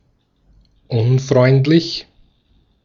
Ääntäminen
IPA : /ˌɔfˈhænd/